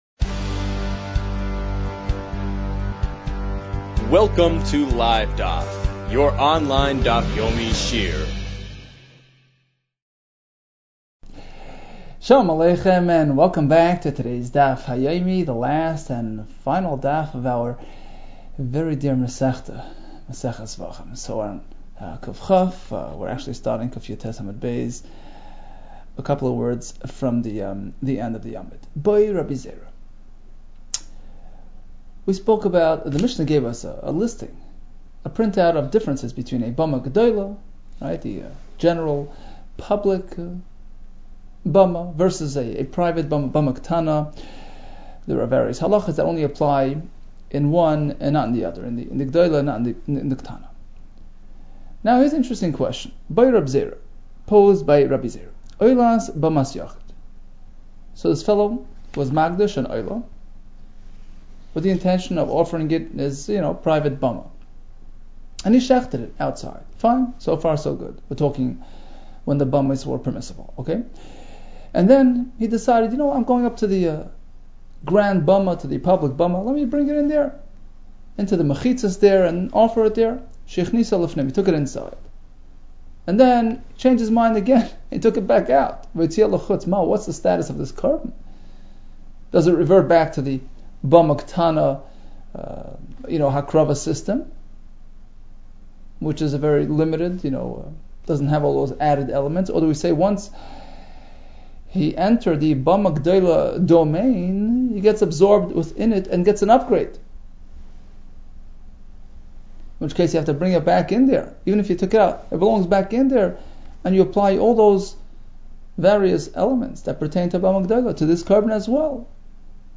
Zevachim 119 - זבחים קיט | Daf Yomi Online Shiur | Livedaf